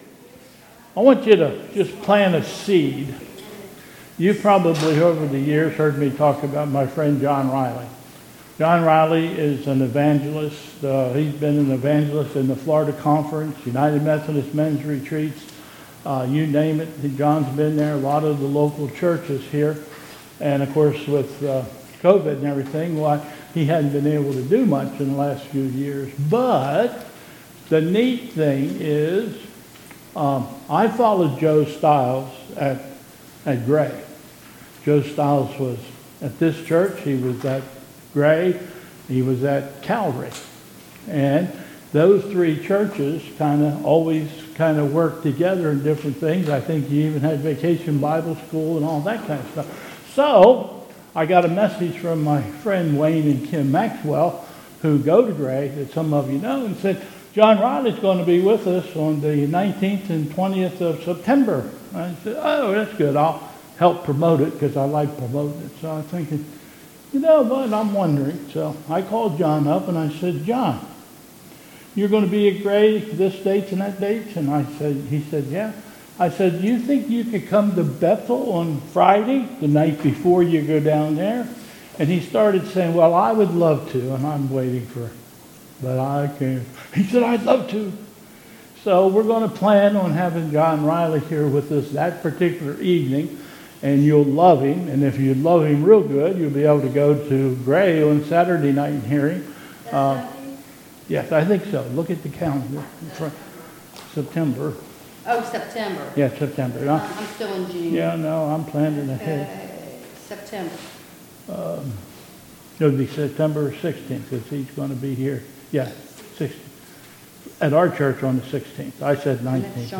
2022 Bethel Covid Time Service
Announcements